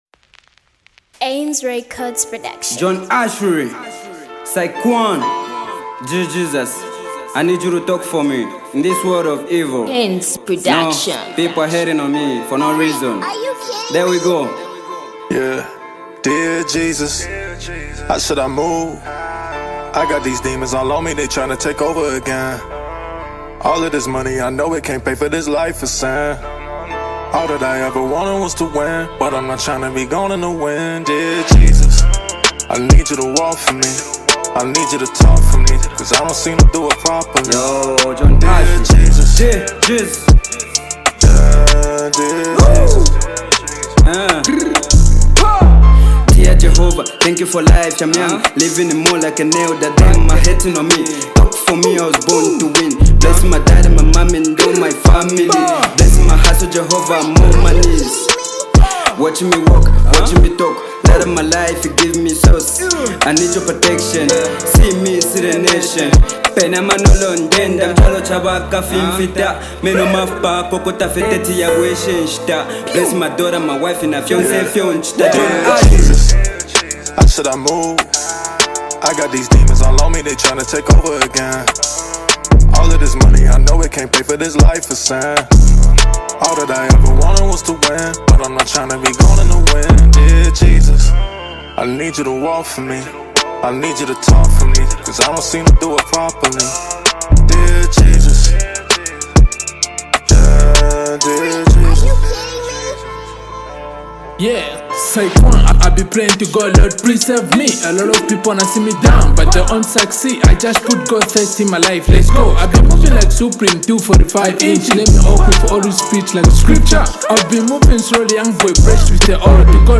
Hip hop gospel